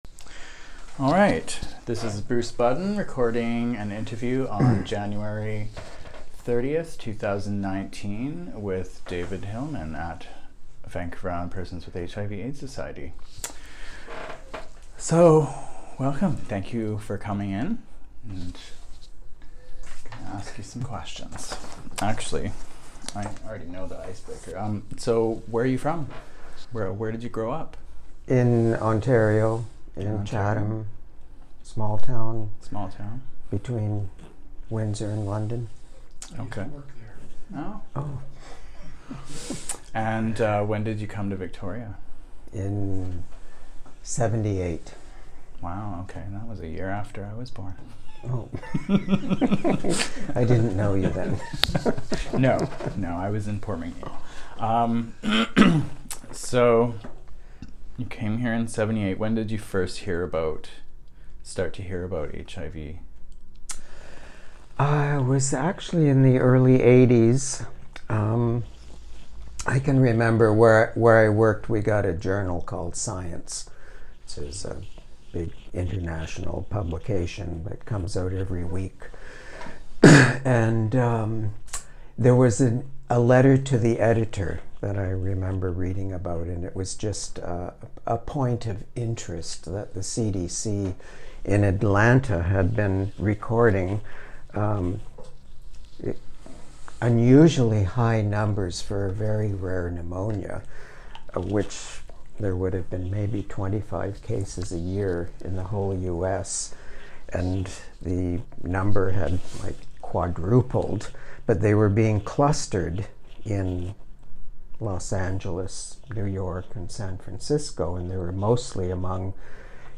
Interview
Location: Victoria.